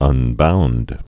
(ŭn-bound)